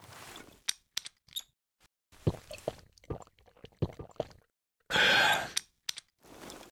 water_drink.ogg